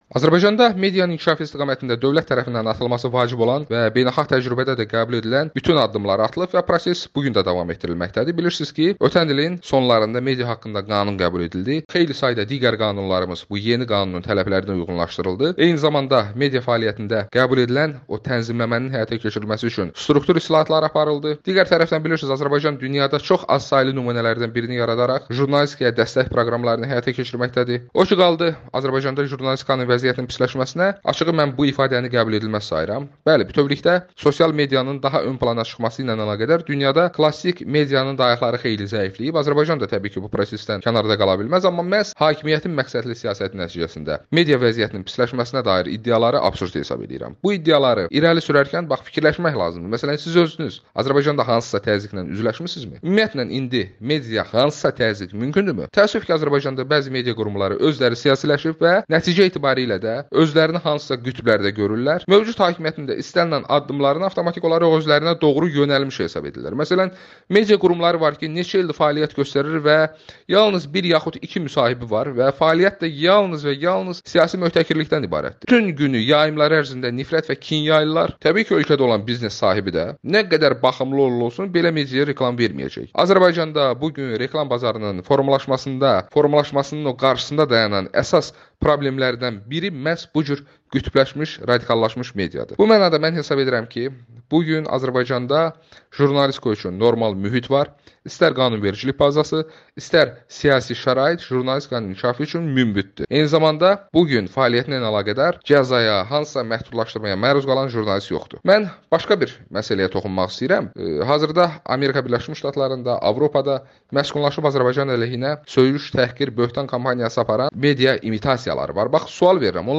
Milli Məclisin Hüquq siyasəti və dövlət quruculuğu komitəsinin üzvü, deputat Bəhruz Məhərrəmov Amerikanın Səsinə müsahibəsində deyir ki, Azərbaycanda medianın inkişafı istiqamətində dövlət tərəfindən atılması vacib olan və beynəlxalq təcrübədə də qəbul edilən bütün addımlar atılır və proses bu gün də davam etdirilməkdədir.